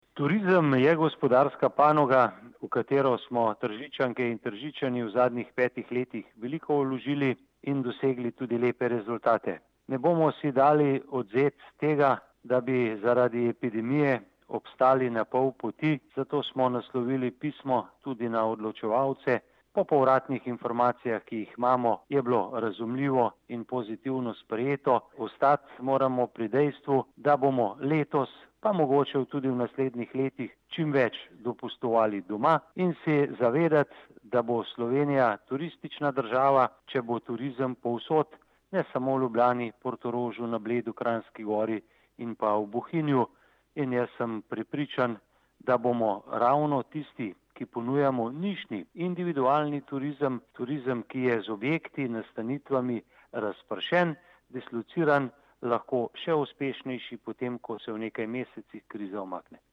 izjava_mag.borutsajoviczupanobcinetrzic_turizem.mp3 (1,4MB)